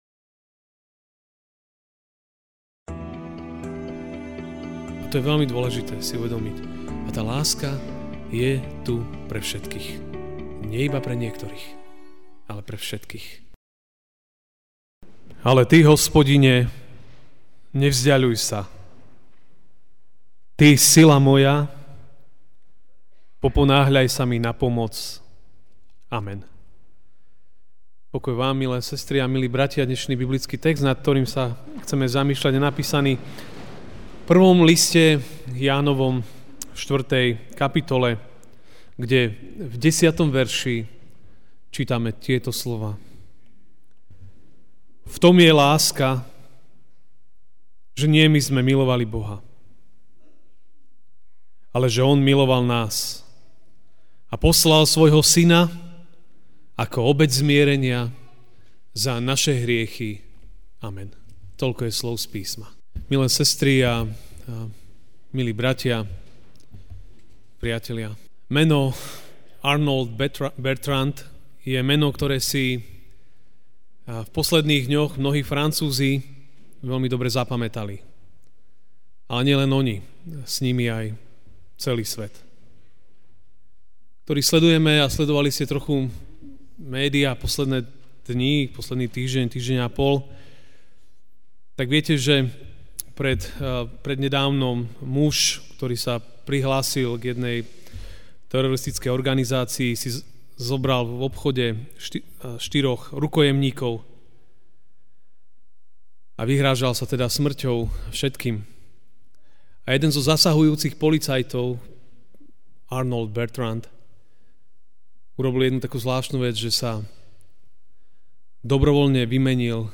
Ranná kázeň: Láska pre každého (1 list Jána 4,10) 'V tom je láska, že nie my sme milovali Boha, ale že On miloval nás a poslal svojho Syna ako obeť zmierenia za naše hriechy.'